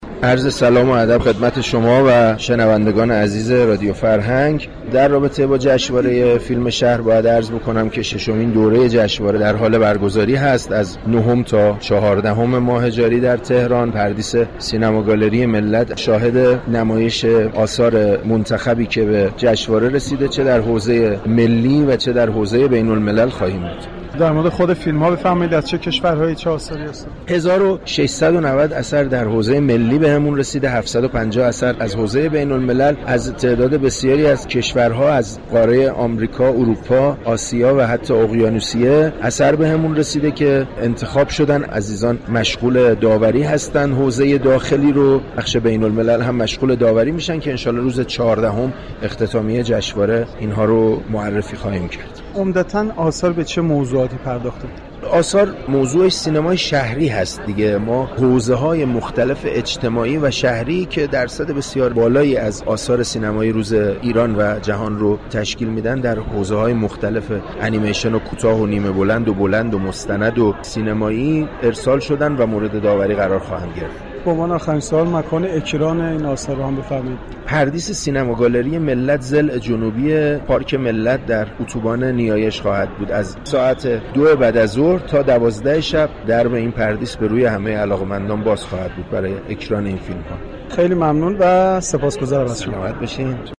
در گفتگوی اختصاصی با گزارشگر رادیو فرهنگ